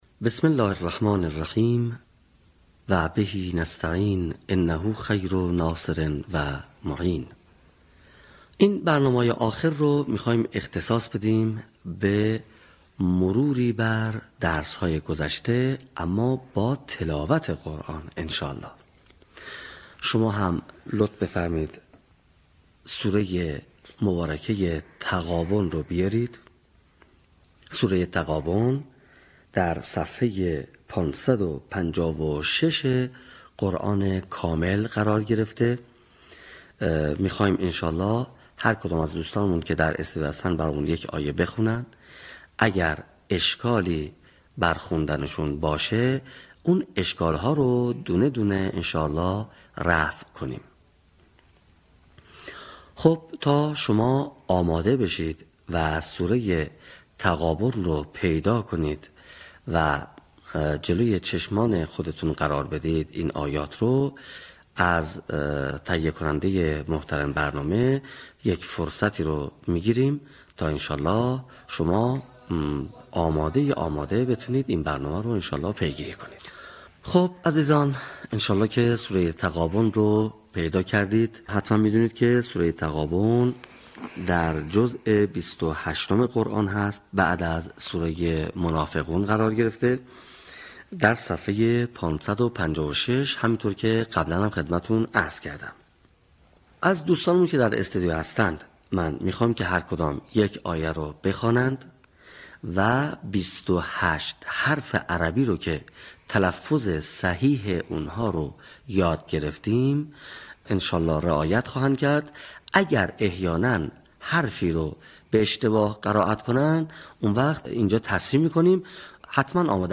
صوت | آموزش قرائت آیات ۱ تا ۴ سوره تغابن